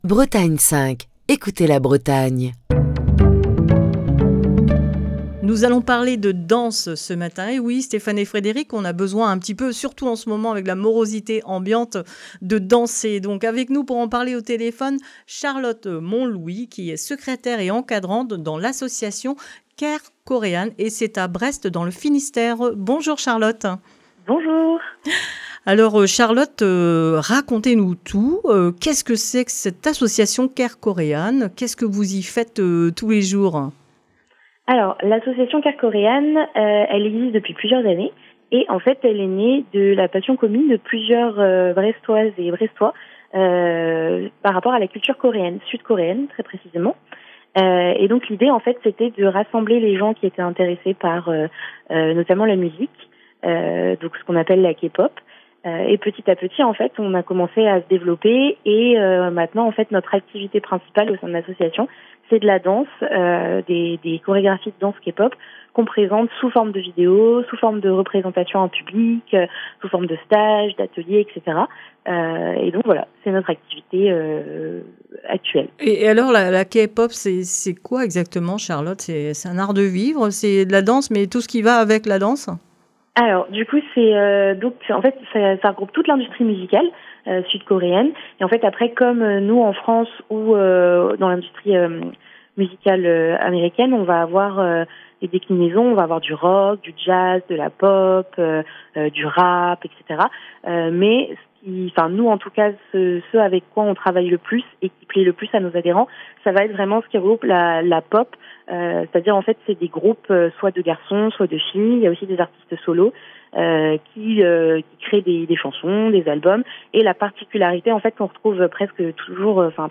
Émission du 16 novembre 2022.